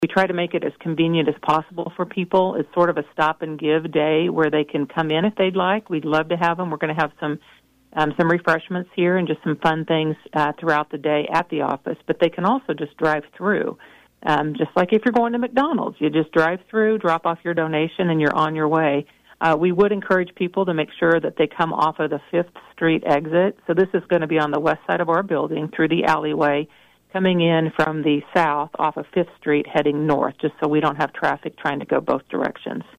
During an interview on KVOE’s Talk of Emporia Friday